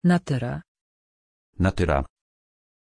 Pronunciation of Natyra
pronunciation-natyra-pl.mp3